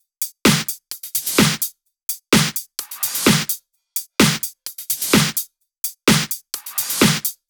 VFH2 128BPM Tron Quarter Kit 4.wav